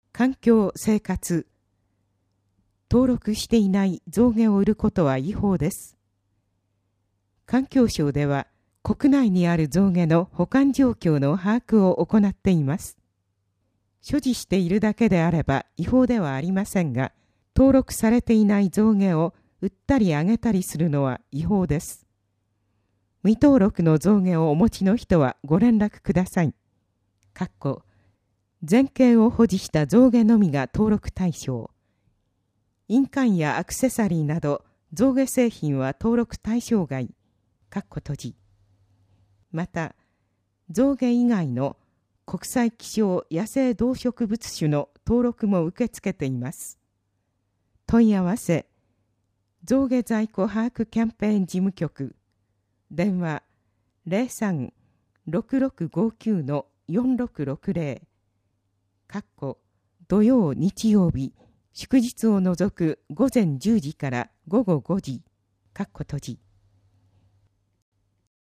声の広報つばめ2018年8月15日号